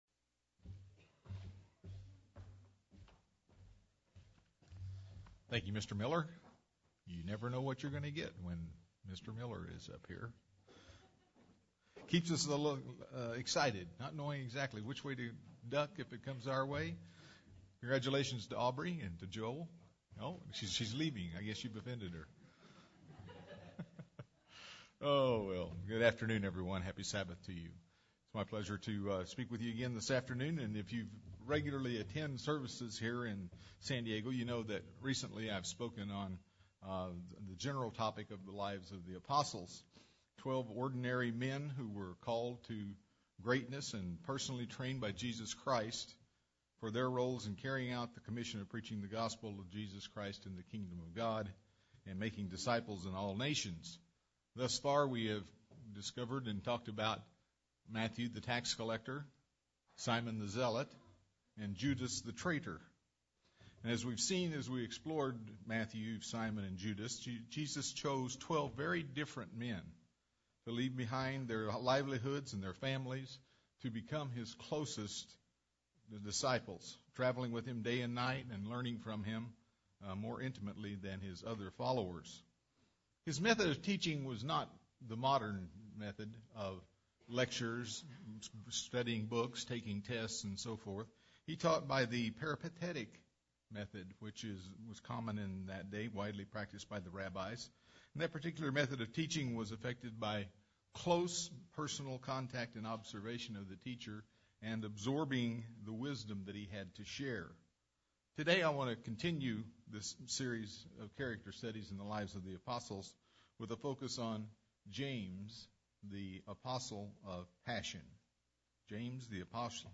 UCG Sermon Studying the bible?
Given in San Diego, CA